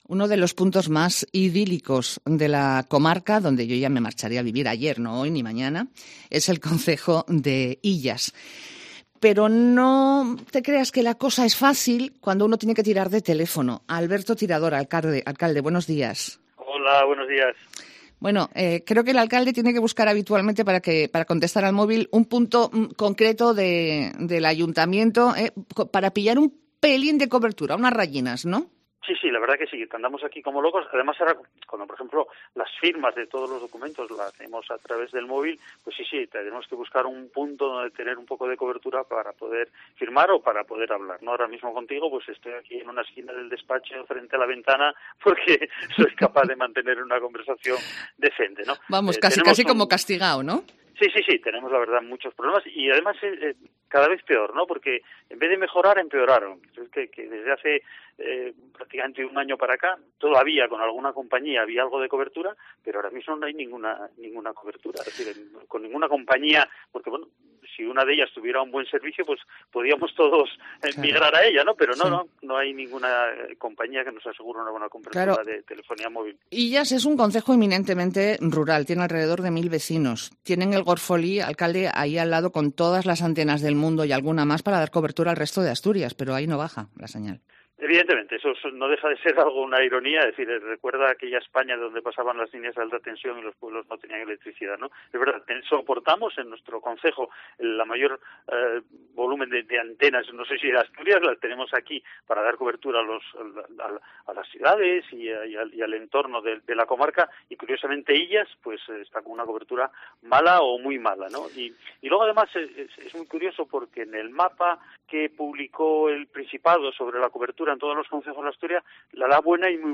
Entrevista con Alberto Tirador, alcalde de Illas
Tanto que el alcalde, Alberto Tirador, ha tenido que buscar una esquina concreta de su despacho para poder charlar con nosotros.